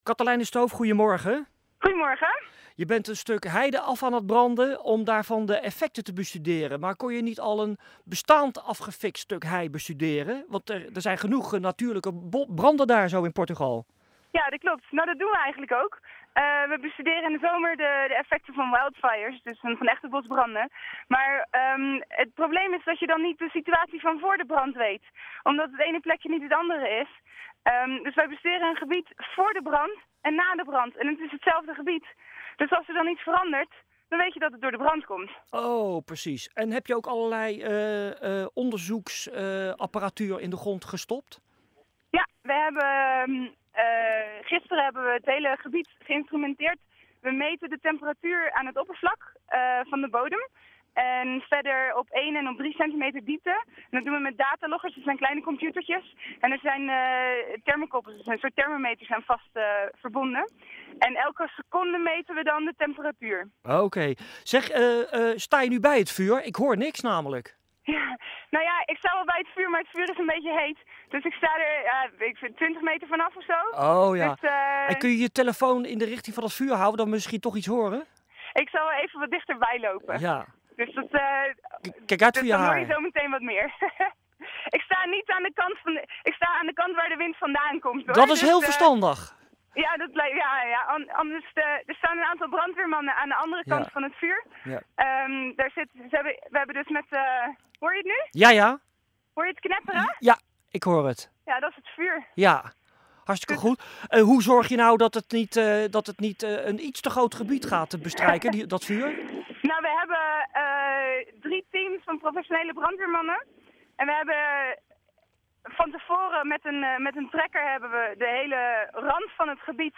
The following interviews were broadcast on Dutch, Belgian and Portuguese radio.